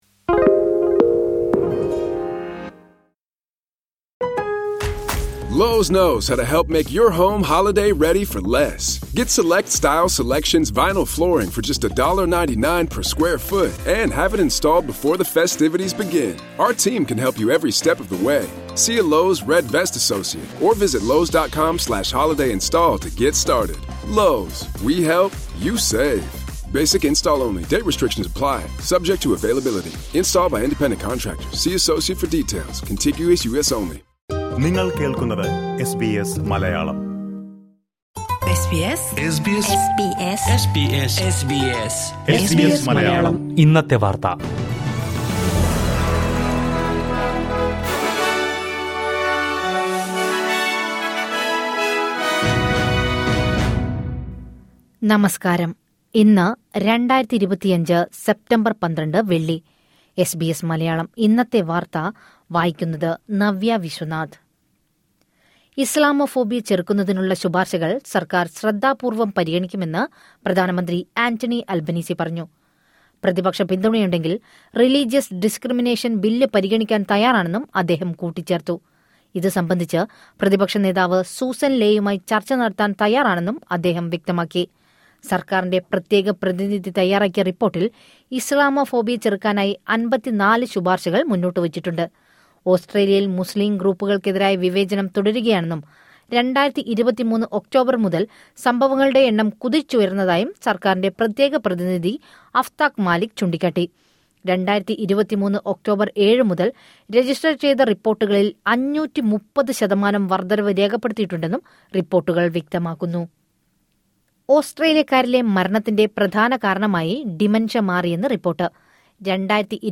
2025 സെപ്റ്റംബർ 12ലെ ഓസ്ട്രേലിയയിലെ ഏറ്റവും പ്രധാന വാർത്തകൾ കേൾക്കാം...